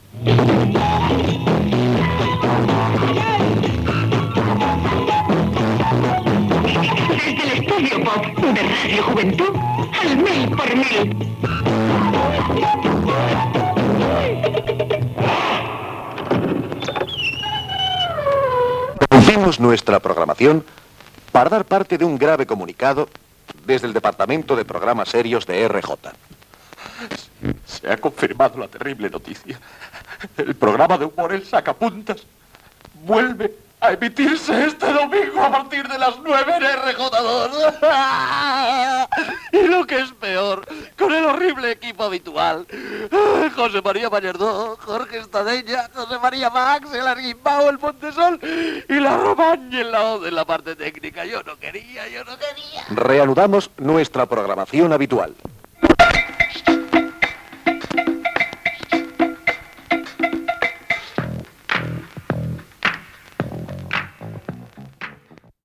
Indicatiu del programa, avís que el programa "El sacapuntas" retorna a la programació de RJ2 amb els noms de l'equip
Entreteniment
FM